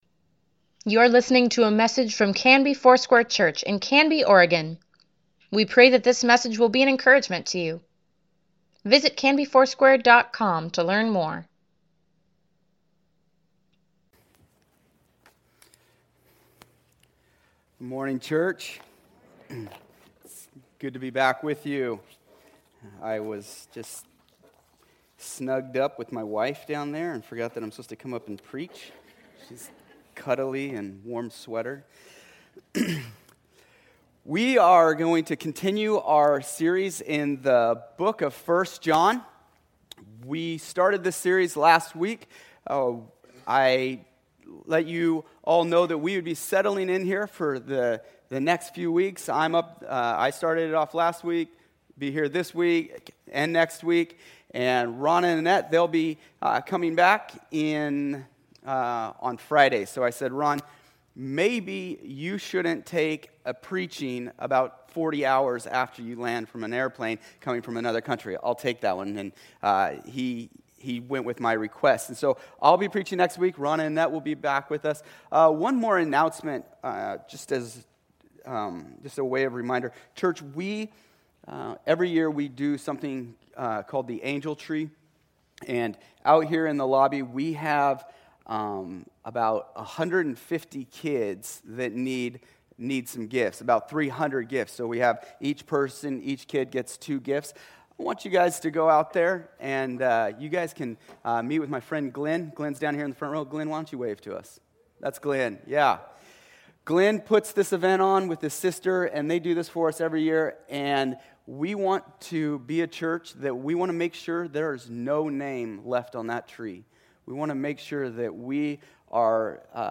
Weekly Email Water Baptism Prayer Events Sermons Give Care for Carus 1 John - pt. 2 November 17, 2019